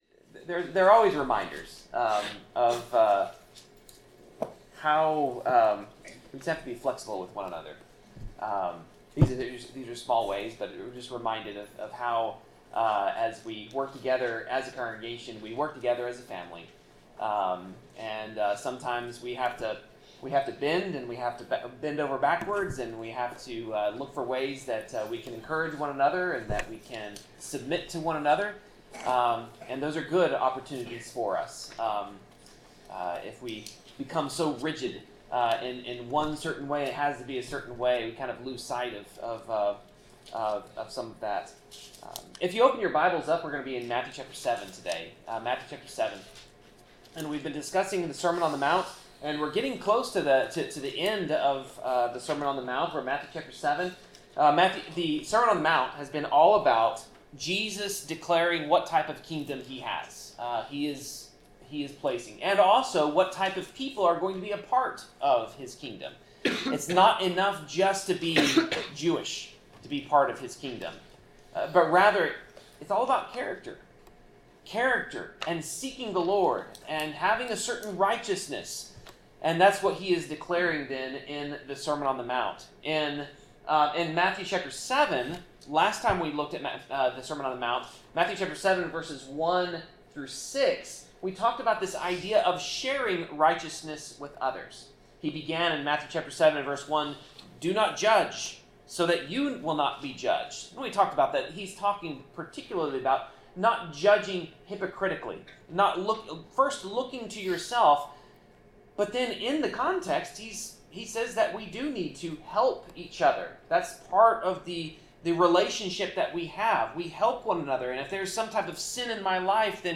Passage: Matthew 7:7-12 Service Type: Sermon